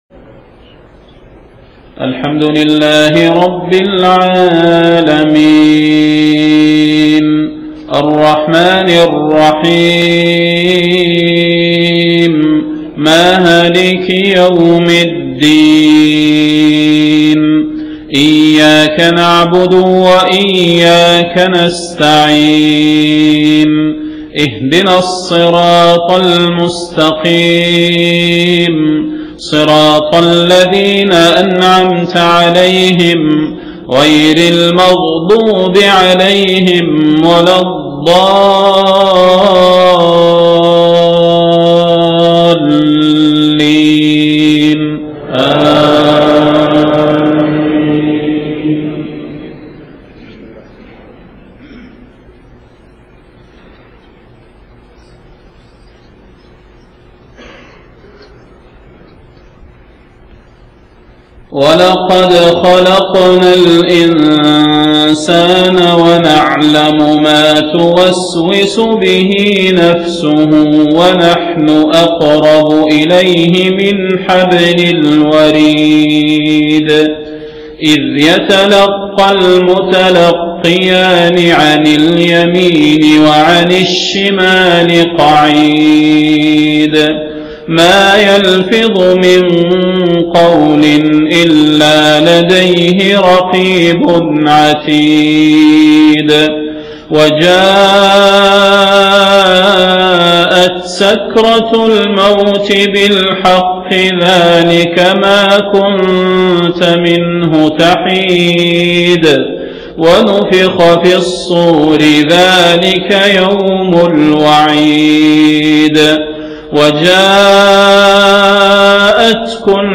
صلاة المغرب 3 ربيع الاخر 1430هـ من سورة ق 16-35 > 1430 🕌 > الفروض - تلاوات الحرمين